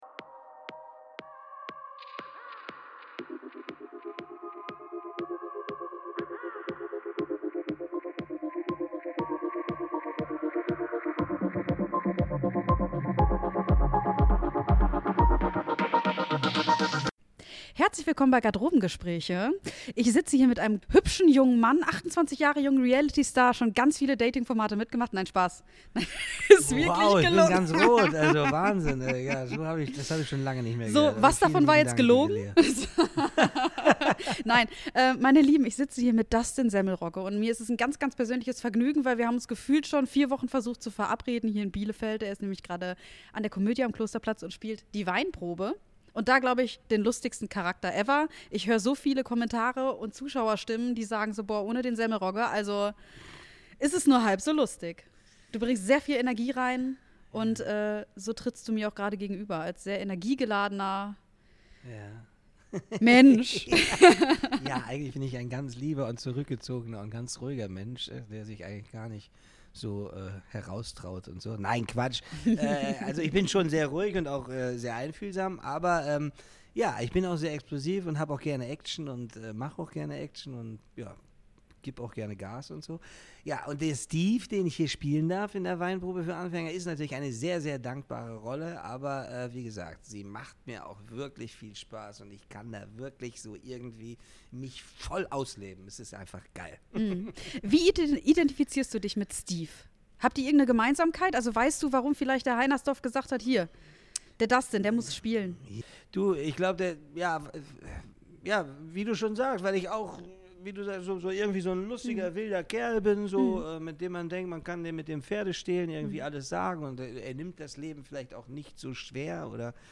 In entspannter Atmosphäre sprechen die beiden offen über seinen Weg als Schauspieler, das Leben vor und hinter der Kamera sowie persönliche Erfahrungen aus vielen Jahren im Film- und Fernsehgeschäft. Es geht um Rollen, Begegnungen, Herausforderungen im Beruf – und um ehrliche Gedanken abseits von Schlagzeilen und Klischees. Ein Gespräch mit Tiefe, Humor und Nähe, das zeigt, wie viel mehr hinter dem Menschen auf der Leinwand steckt.